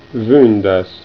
ü Come in tedesco Müller. L'audio riproduce vündas (undici)